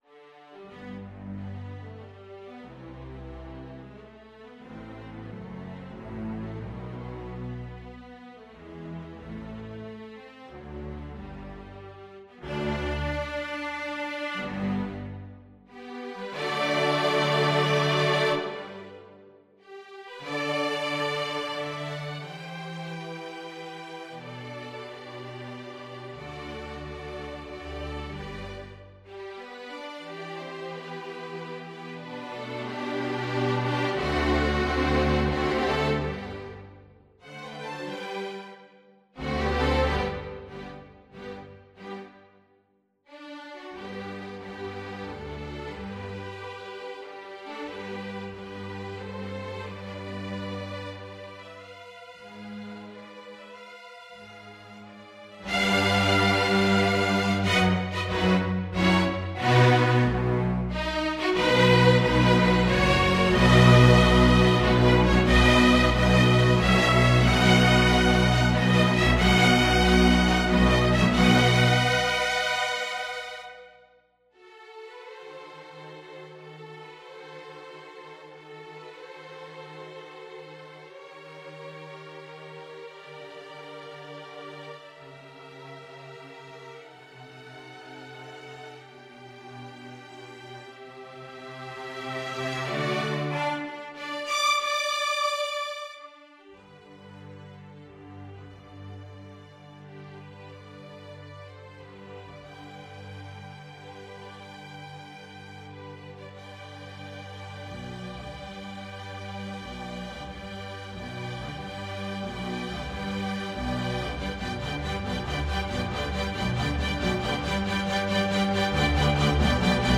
Free Sheet music for String Ensemble
Violin 1Violin 2ViolaCelloDouble Bass
G major (Sounding Pitch) (View more G major Music for String Ensemble )
3/4 (View more 3/4 Music)
Andante con moto ( = 92) (View more music marked Andante con moto)
String Ensemble  (View more Intermediate String Ensemble Music)
Classical (View more Classical String Ensemble Music)